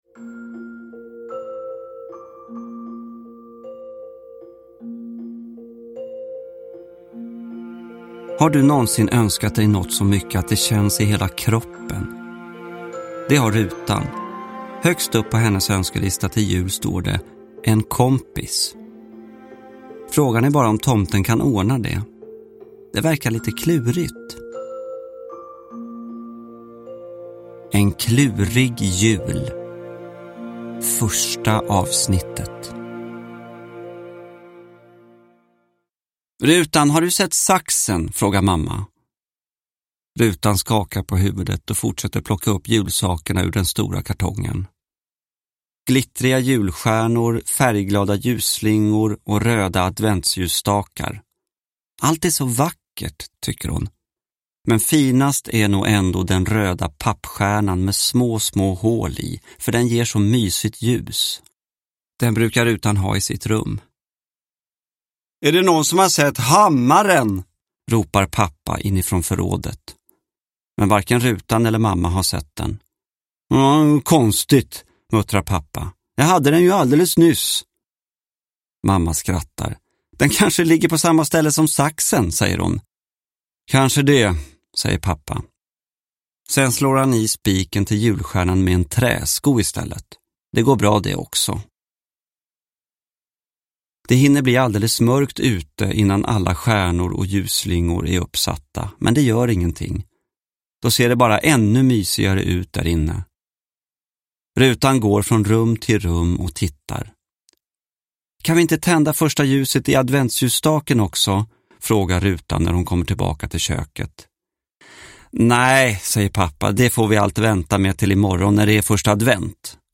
En klurig jul : julsaga i 24 kapitel – Ljudbok – Laddas ner
Uppläsare: Gustaf Hammarsten